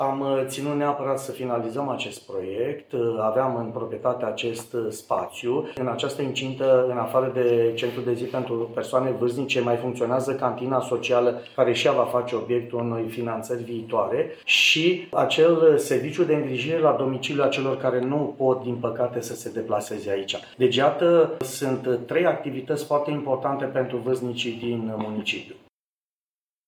Momentul festiv s-a desfășurat la sediul centrului de zi, de pe strada Libertății.
La momentul festiv a fost prezent și primarul municipiului Alba Iulia, Gabriel Pleșa.